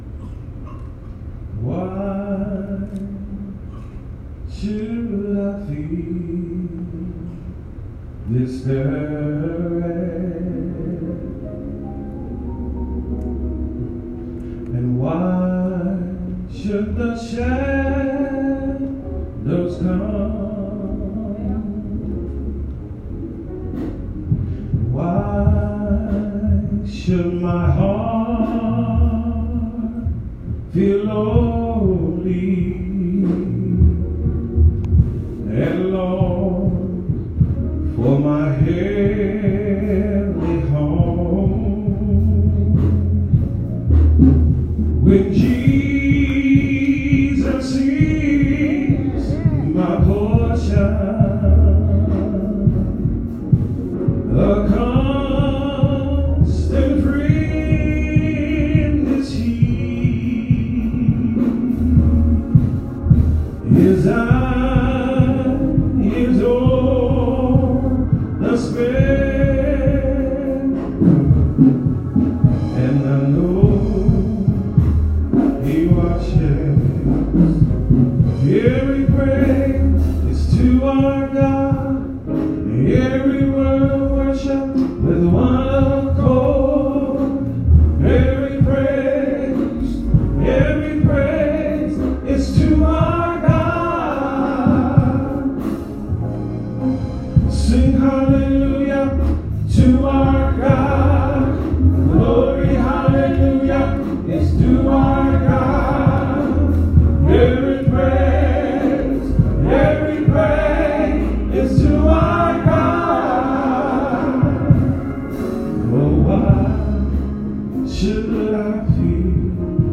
Worship Music:
pre-recorded